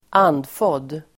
Ladda ner uttalet
Uttal: [²'an:dfåd:]